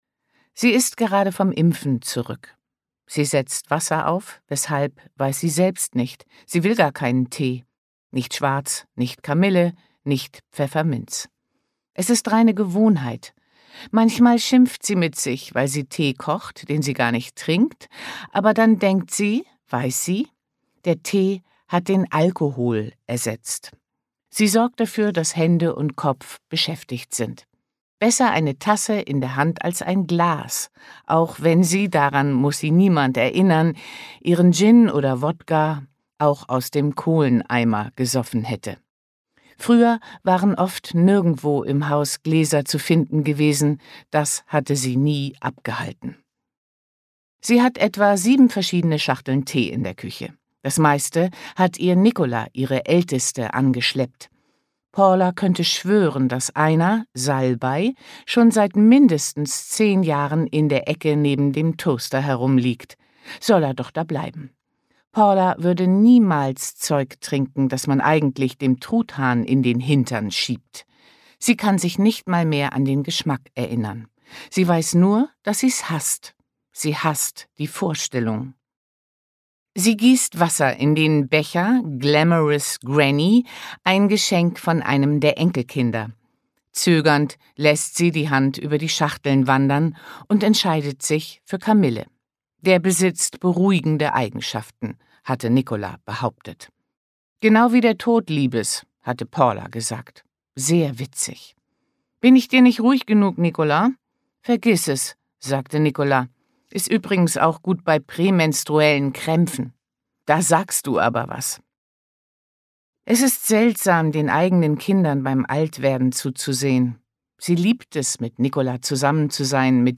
Die Frauen hinter der Tür – Hörbuch
Die-Frauen-hinter-der-Tuer-Hoerprobe.mp3